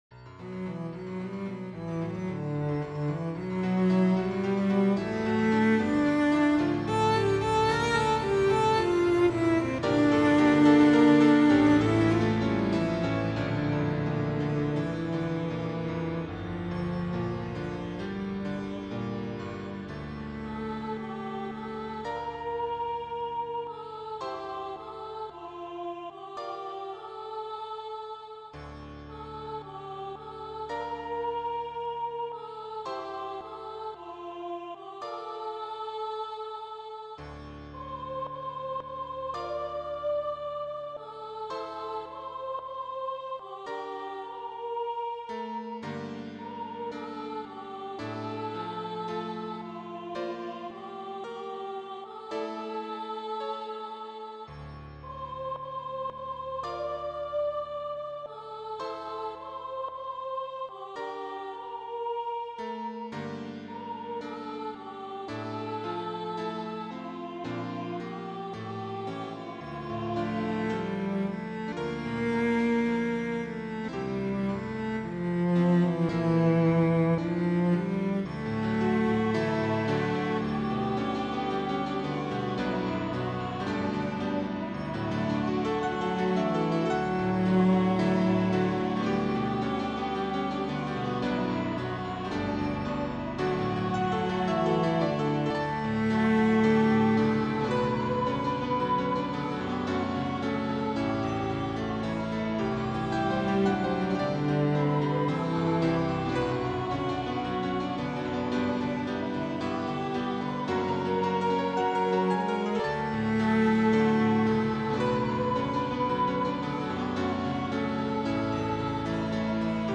Soprano, Cello, and Piano
Hymn arrangement.